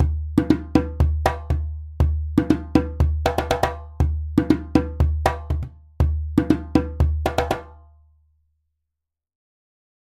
Звуки джембе
На этой странице собраны звуки джембе — традиционного африканского барабана.
Звуковая демонстрация игры на джембе